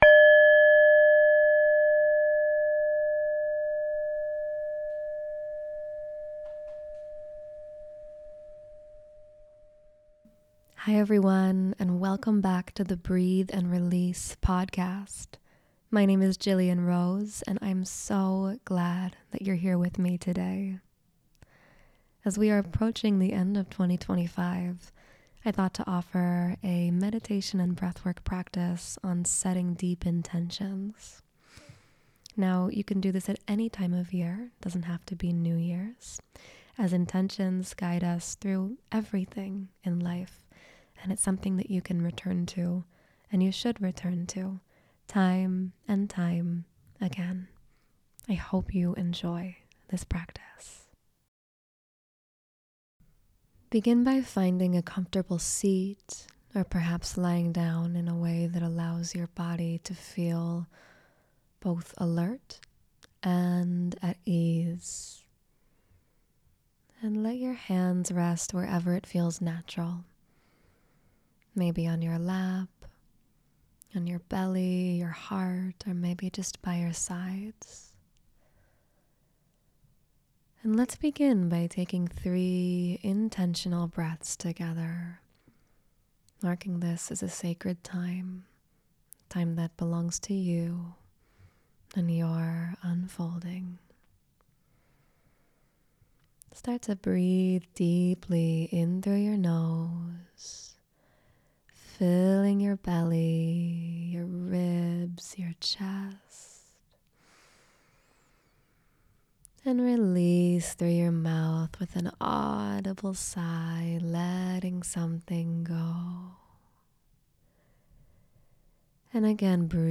Connecting to Your Deepest Intentions: Guided Meditation & Breathwork
In this 15-minute guided meditation, we explore the difference between resolutions and deep intentions.